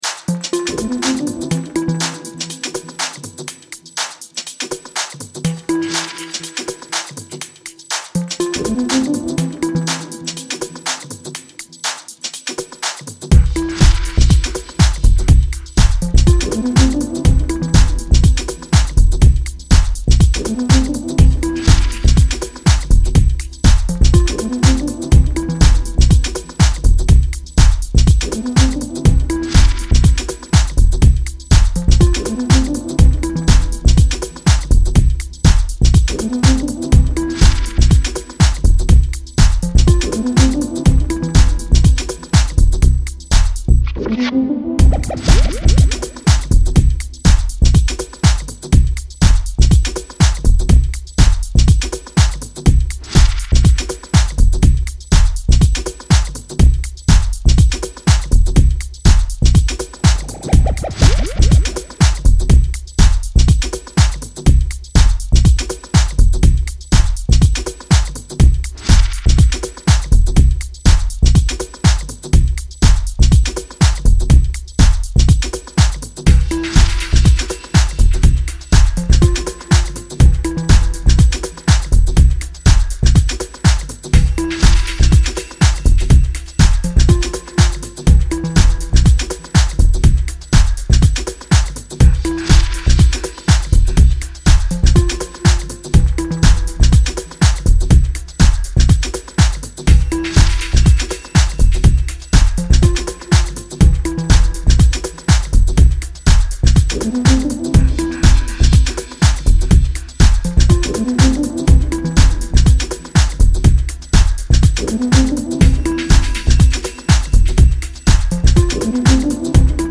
house and garage